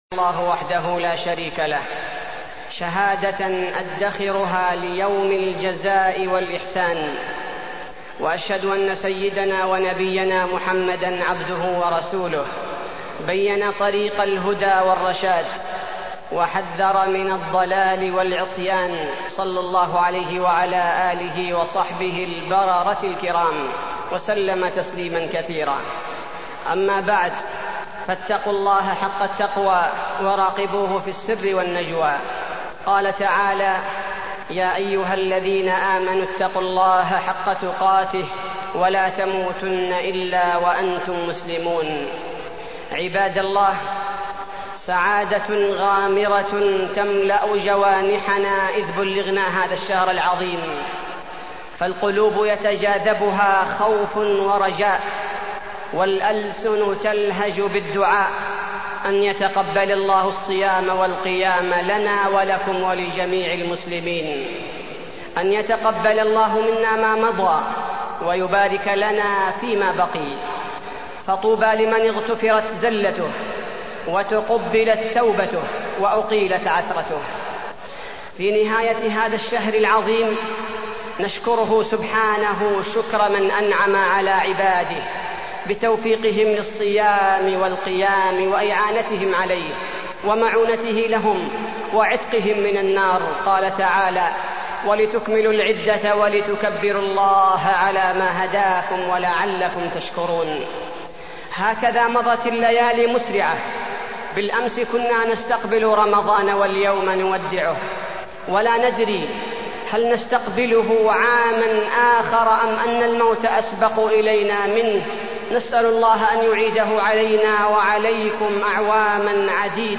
تاريخ النشر ٢٩ رمضان ١٤٢٢ هـ المكان: المسجد النبوي الشيخ: فضيلة الشيخ عبدالباري الثبيتي فضيلة الشيخ عبدالباري الثبيتي الاستغفار